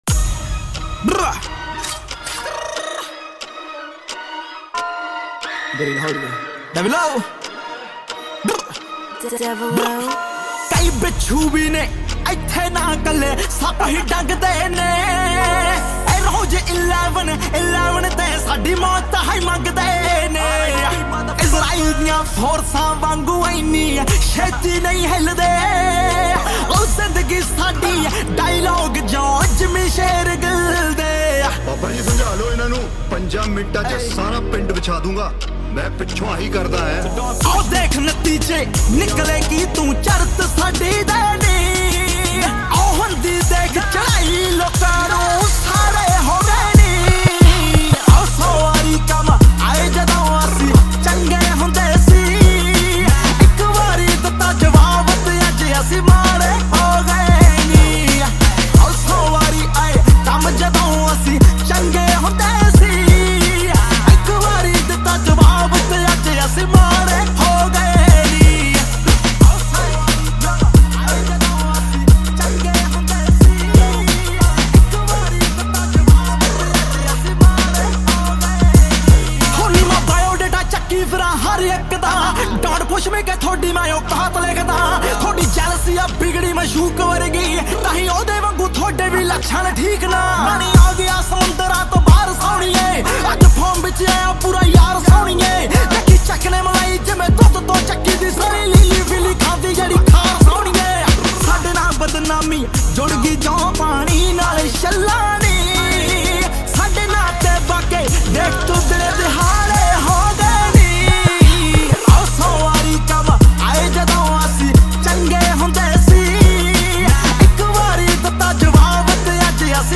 old Punjabi song